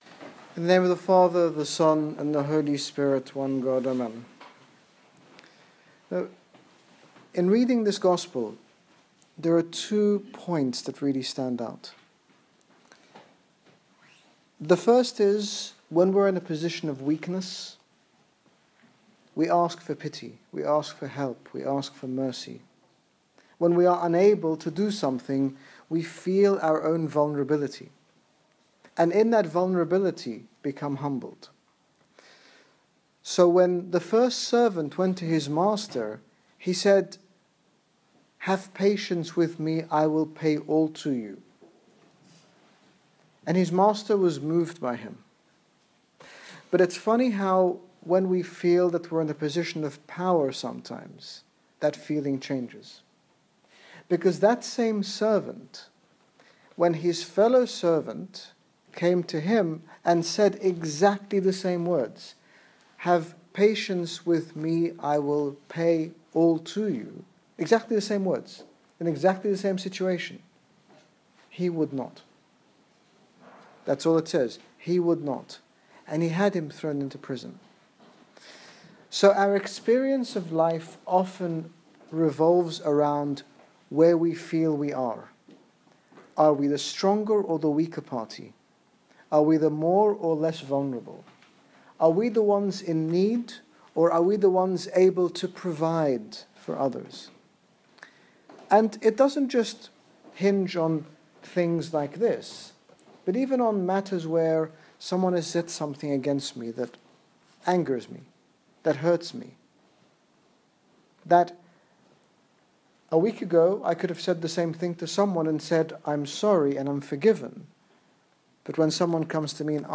In this sermon His Grace Bishop Angaelos, General Bishop of the Coptic Orthodox Church in the United Kingdom, speaks about how we must and can forgive others whether we consider ourselves in a position of strength or weakness.